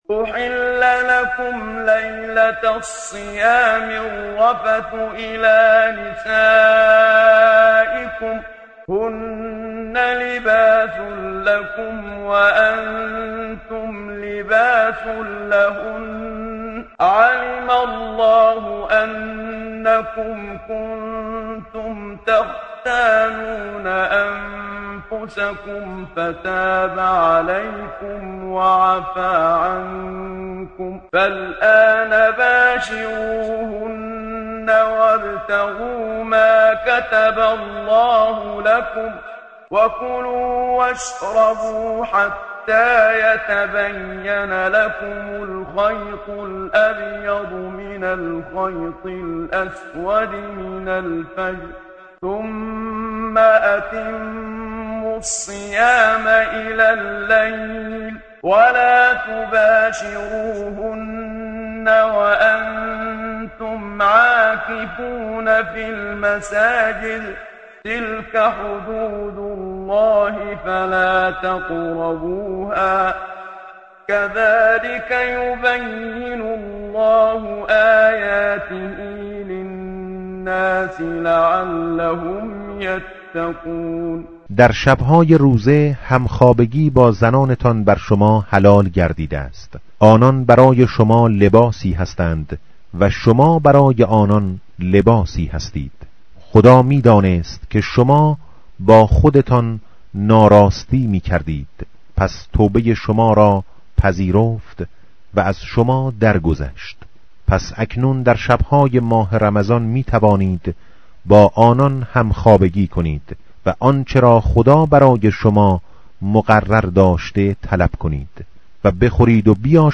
متن قرآن همراه باتلاوت قرآن و ترجمه
tartil_menshavi va tarjome_Page_029.mp3